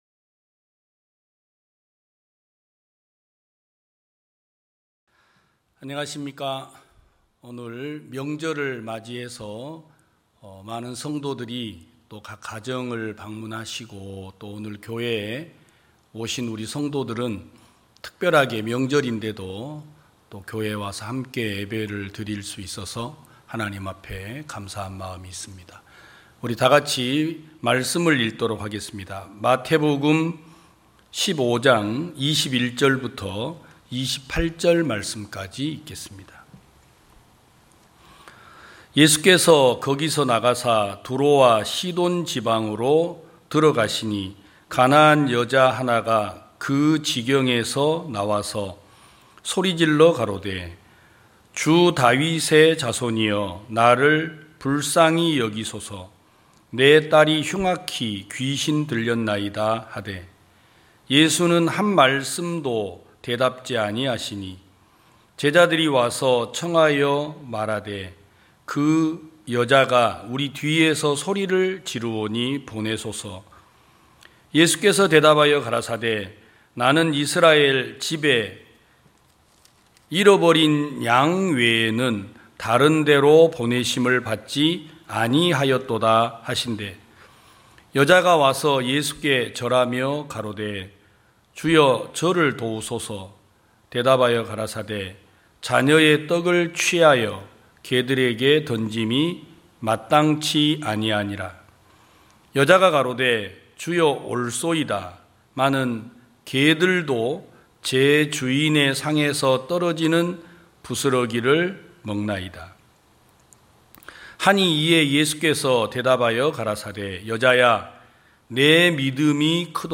2022년 09월 11일 기쁜소식부산대연교회 주일오전예배
성도들이 모두 교회에 모여 말씀을 듣는 주일 예배의 설교는, 한 주간 우리 마음을 채웠던 생각을 내려두고 하나님의 말씀으로 가득 채우는 시간입니다.